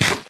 Sound / Minecraft / random / eat3